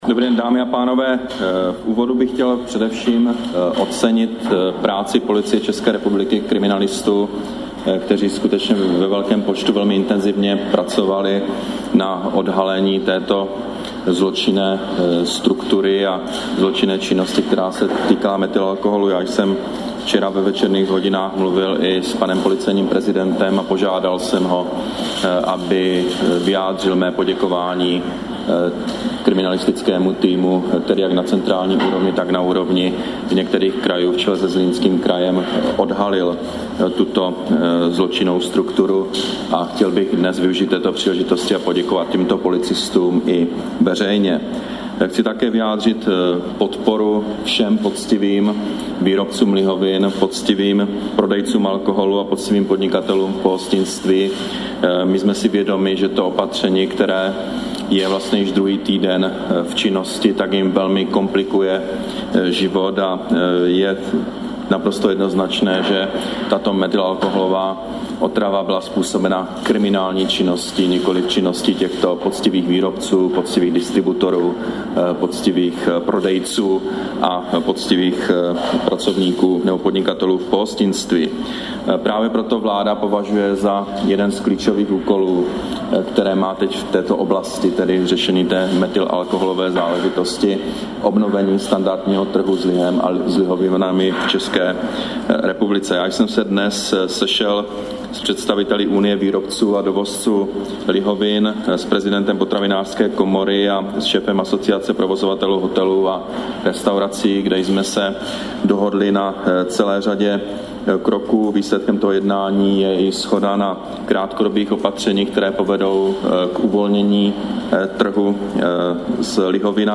Tisková konference premiéra Petra Nečase po jednání se zástupci výrobců lihovin, hotelů a restaurací, 25. září 2012